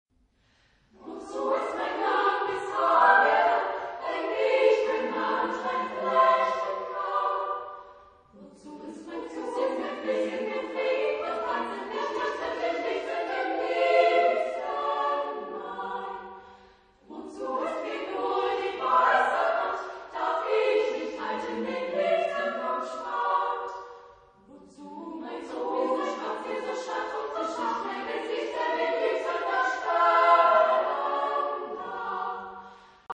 Genre-Stil-Form: weltlich
Chorgattung: SSAA  (4 Frauenchor Stimmen )
Instrumente: Klavier (1)
Aufnahme Bestellnummer: 7. Deutscher Chorwettbewerb 2006 Kiel